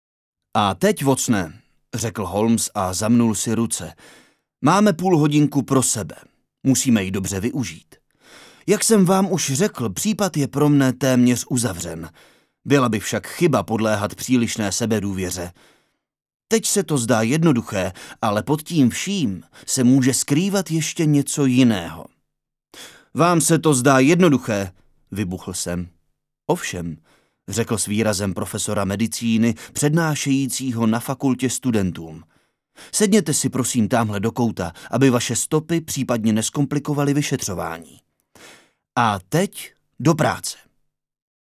ukázka audio kniha verze 2
ukazka-audio-kniha-verze-2.mp3